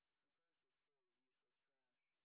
sp03_white_snr20.wav